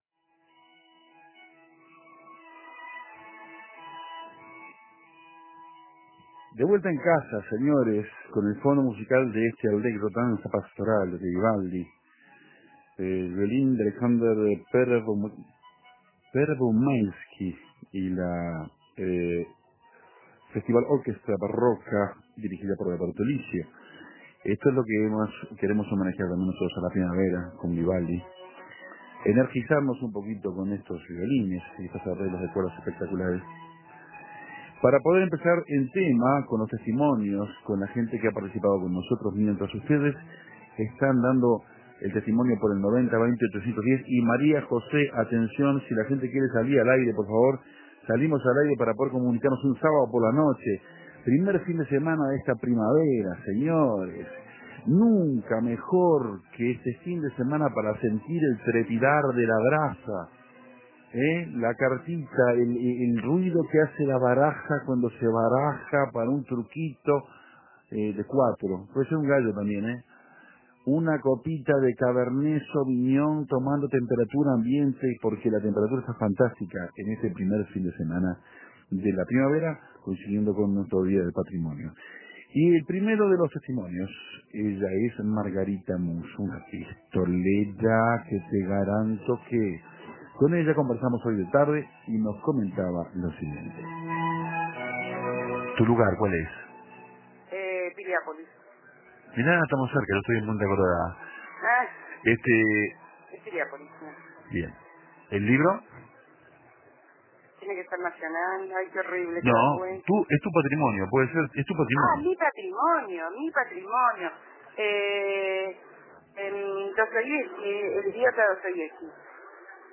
Un libro, un disco, un lugar y un nombre fue la consigna para declarar patrimonio personal. Tantas opciones como oyentes, tantos testimonios como llamadas al aire.